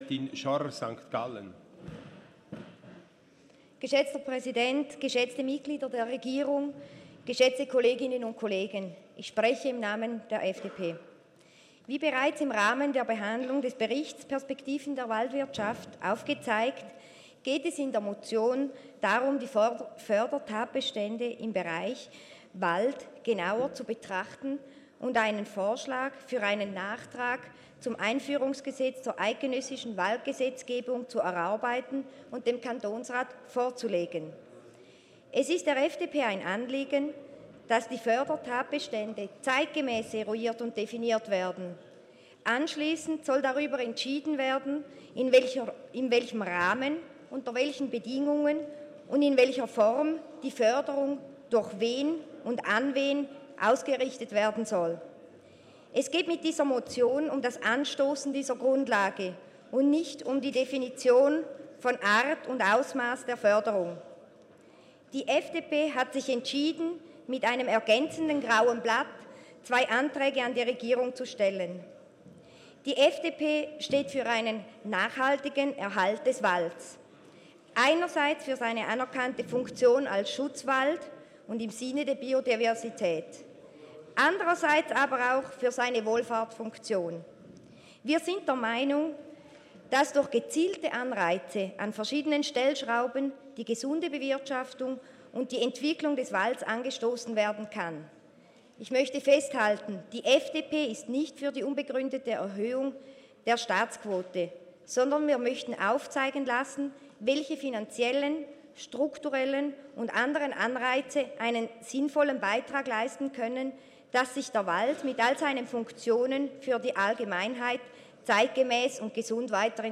Session des Kantonsrates vom 11. bis 13. Juni 2019